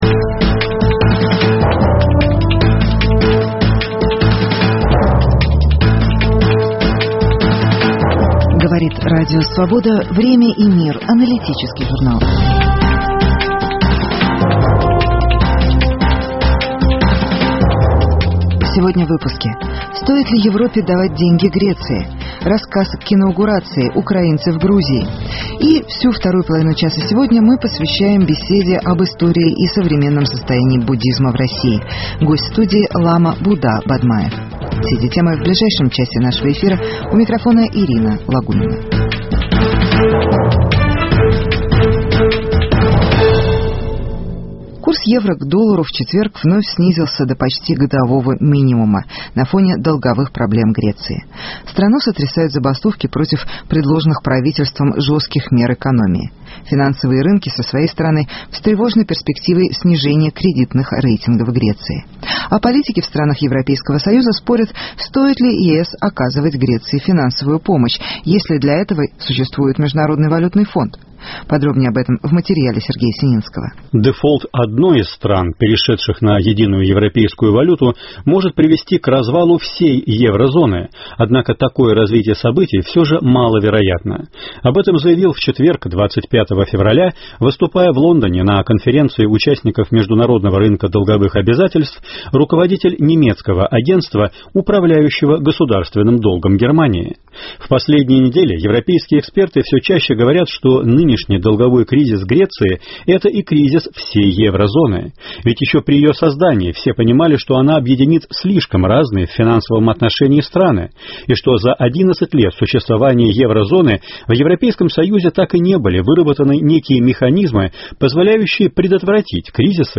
История российского буддизма. Беседа